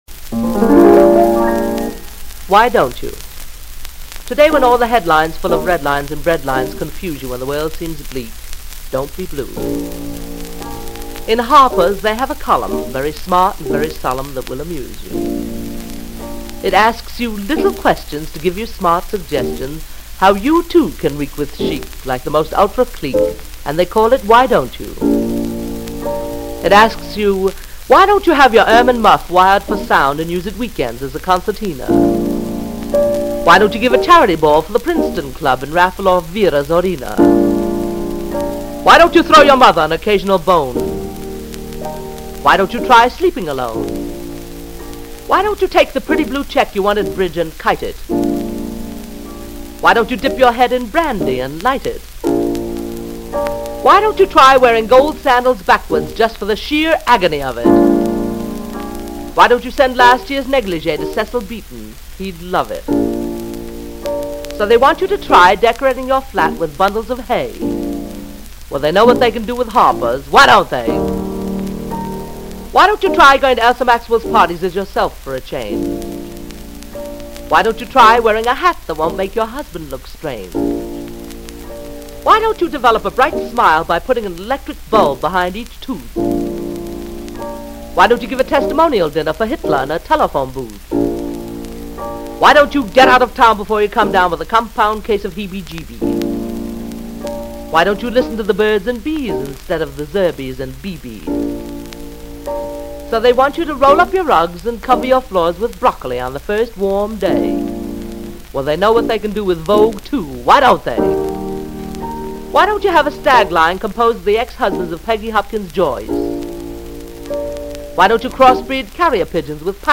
--w. piano, New York City, Oct., 1939 Reeves Sound Studios.